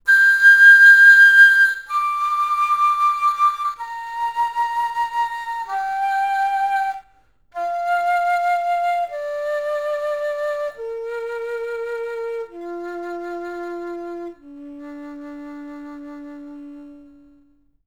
Play Flute coded
wavelet filter of length=4, 64 subbands